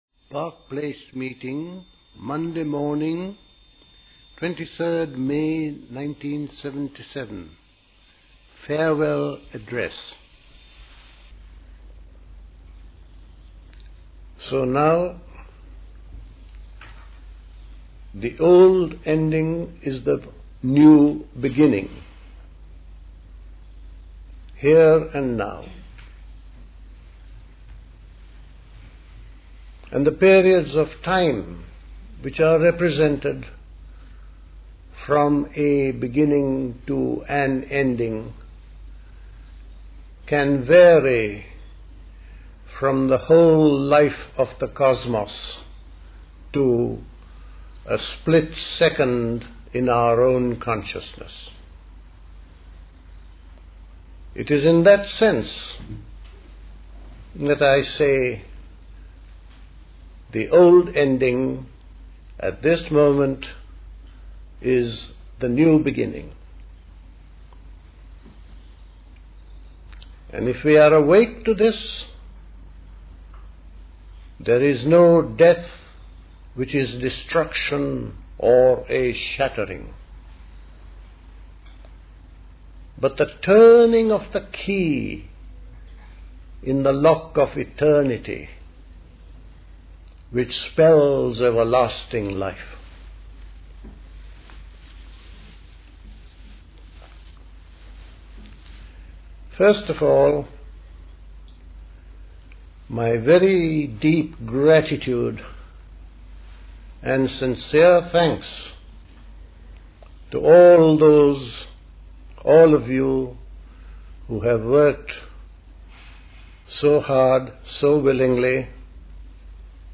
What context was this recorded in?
The Park Place Summer School Talks